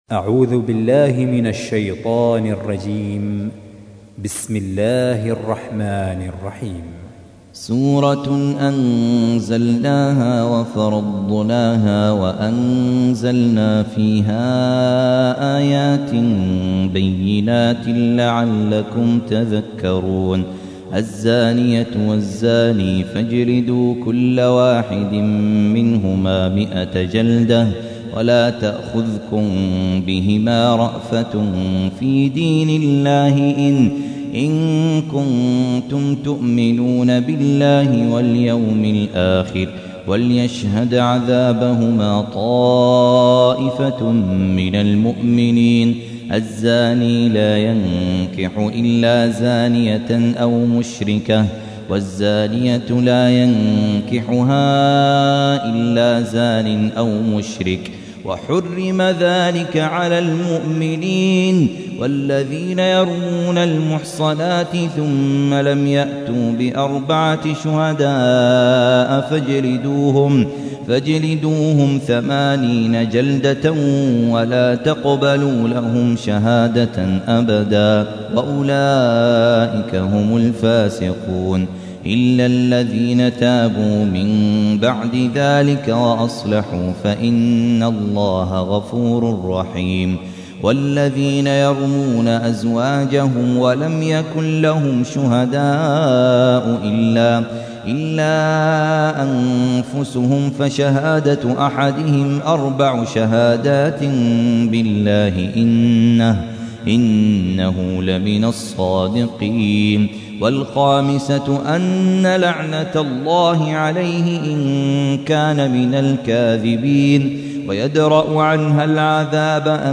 تحميل : 24. سورة النور / القارئ خالد عبد الكافي / القرآن الكريم / موقع يا حسين